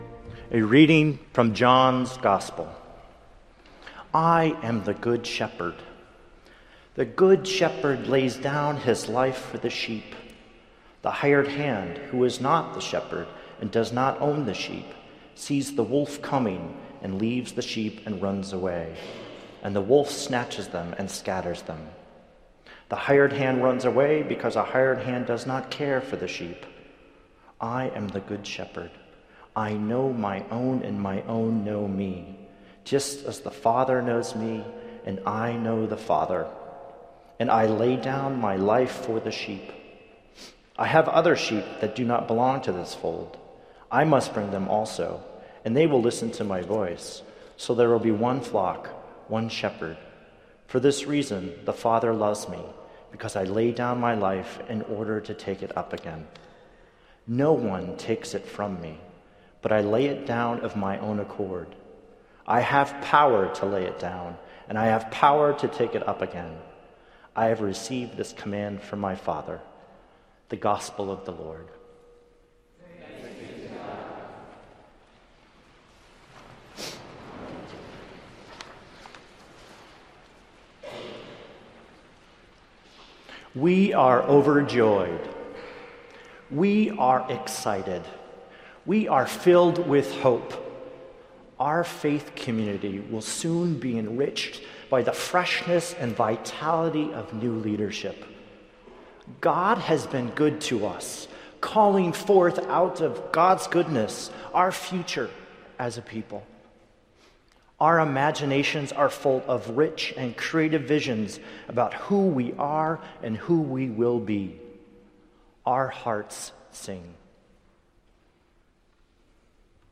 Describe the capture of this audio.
Boe Memorial Chapel